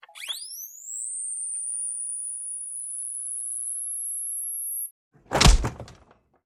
Звук заряда дефибриллятора с ударом